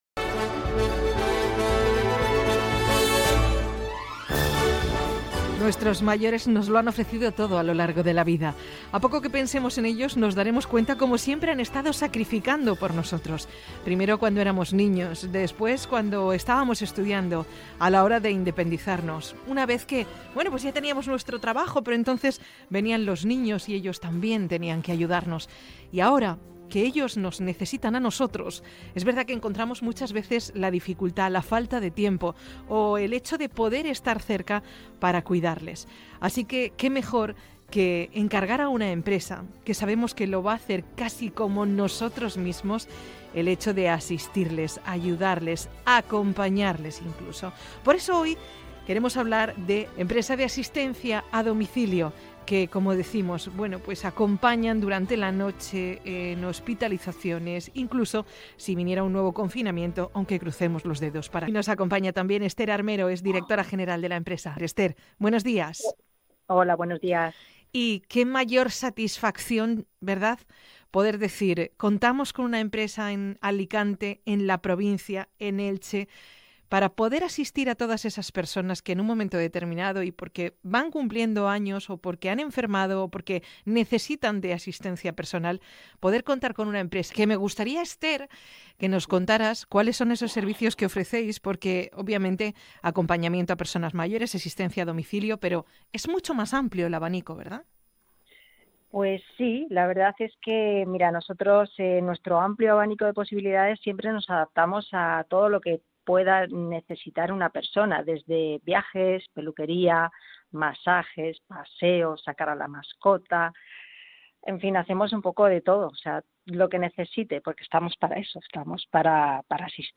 Extracto de la entrevista